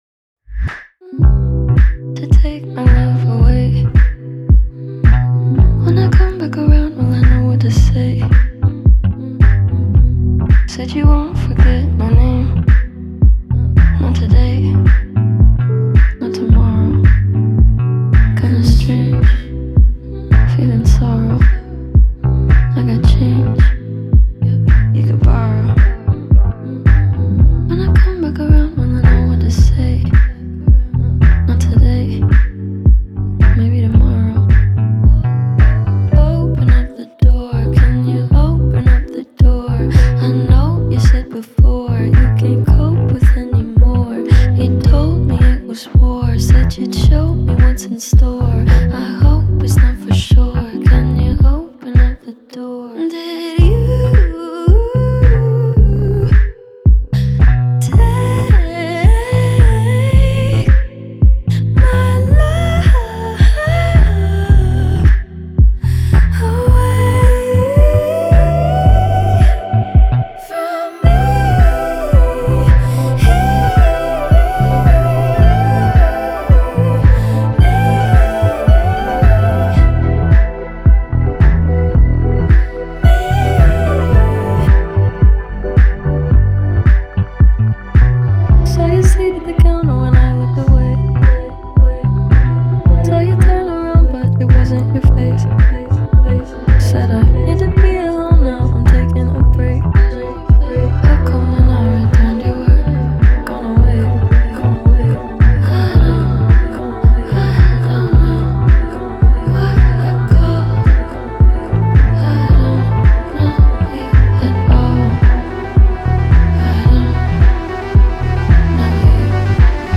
• Жанр: Indie